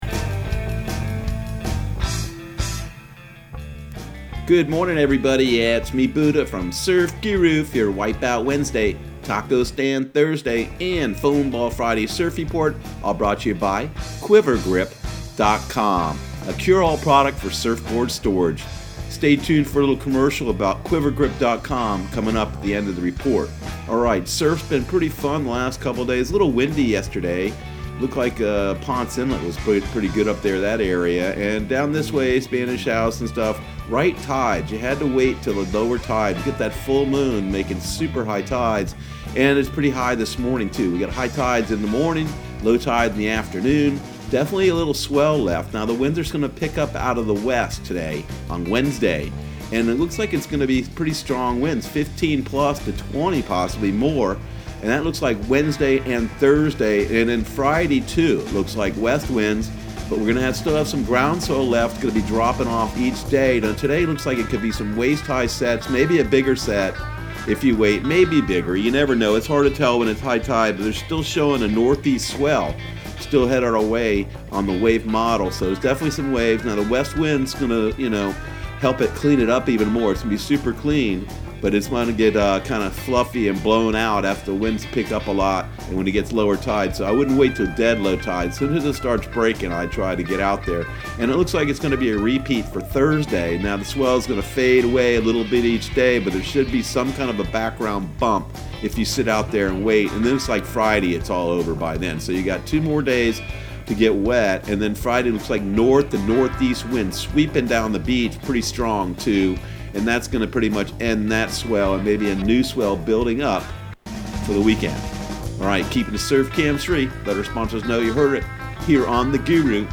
Surf Guru Surf Report and Forecast 04/08/2020 Audio surf report and surf forecast on April 08 for Central Florida and the Southeast.